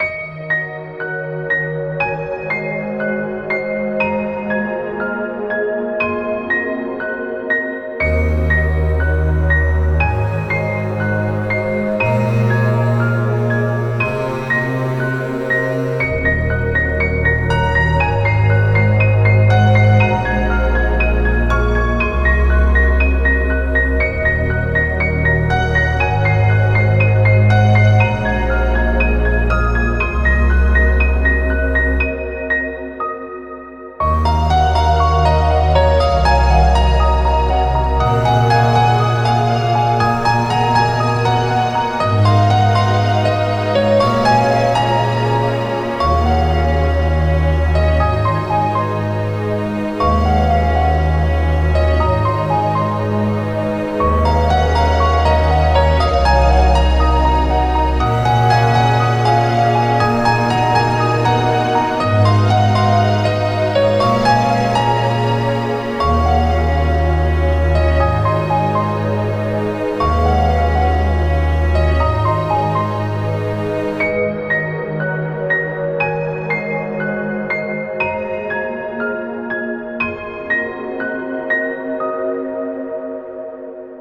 そのため和楽器は使用せずピアノとストリングスを中心とした柔らかく幻想的な音作りになっています。
• テンポ：ゆったりとしたスローテンポ（約120 BPM）
• 構成：ループ対応のシンプルな構成（A-B-A）
• ピアノは柔らかく繊細に
• ストリングスは深みをもたせつつ低域をやさしく支える
フリーBGM 癒し ピアノ 感動 七夕 祈り